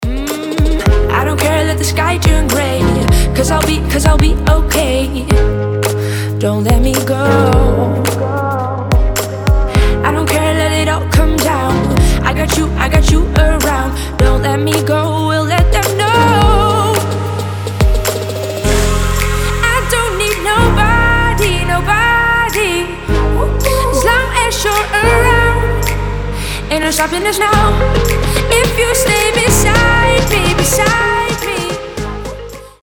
• Качество: 320, Stereo
позитивные
женский вокал
зажигательные
Dance Pop